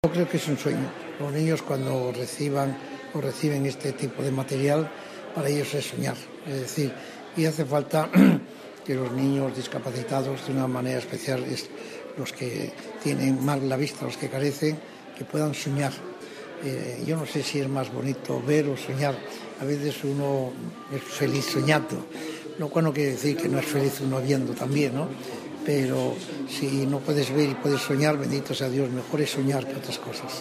el padre Ángel no dudaba de calificar como “un sueño” para sus destinatarios (archivo mp3), un colectivo de pequeños que viven unas circunstancias de violencia, inestabilidad y carencias materiales de todo tipo.